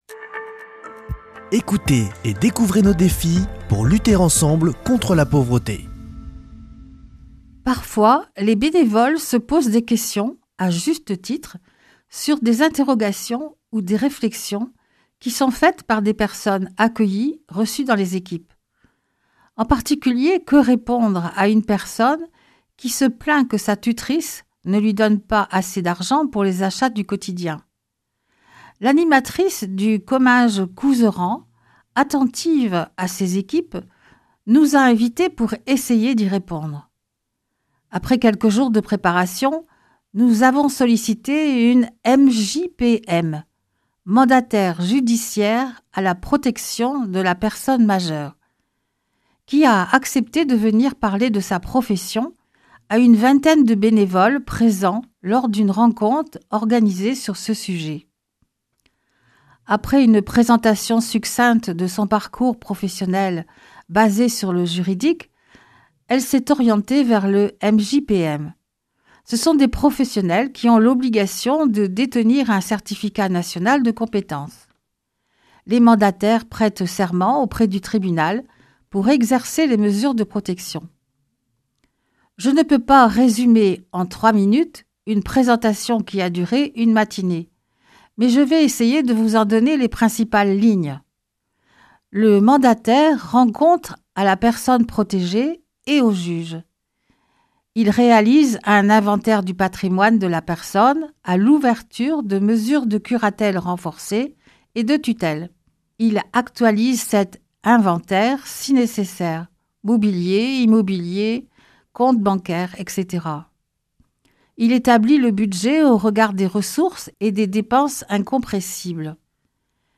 lundi 30 mars 2026 Chronique du Secours Catholique Durée 3 min